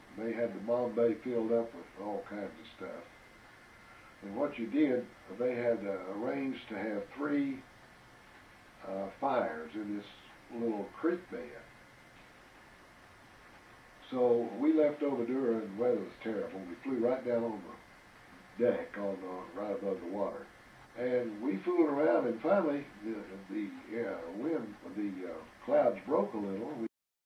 and interview with his father.